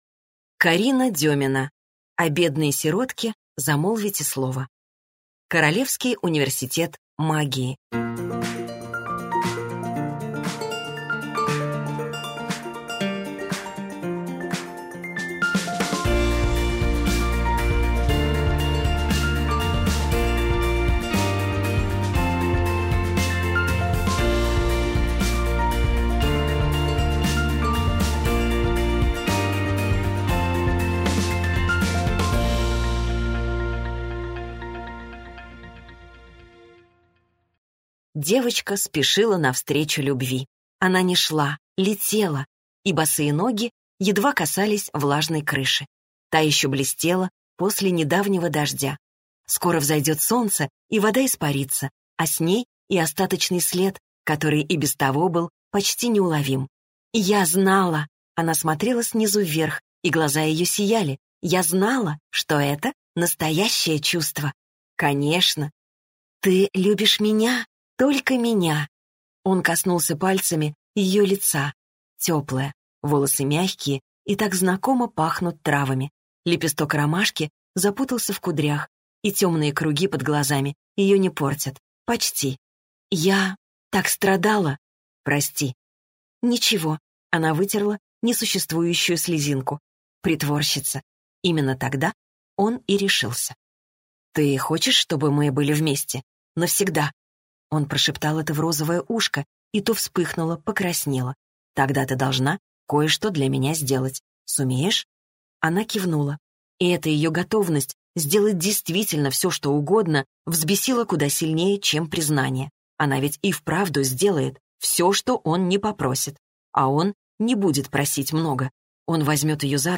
Аудиокнига О бедной сиротке замолвите слово - купить, скачать и слушать онлайн | КнигоПоиск